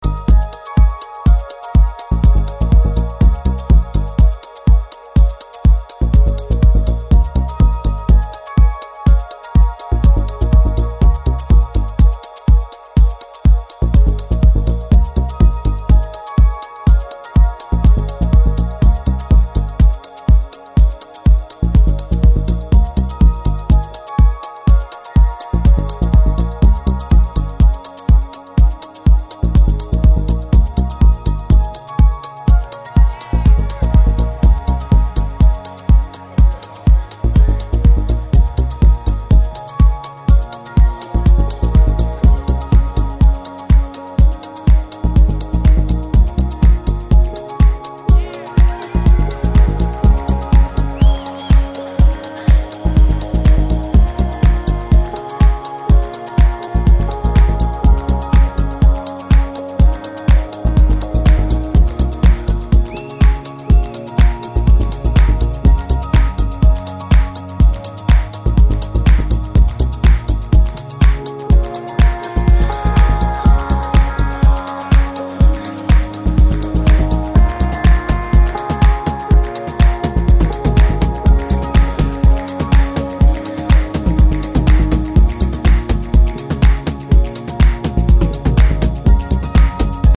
electronic music
typically epic and mind-bending journey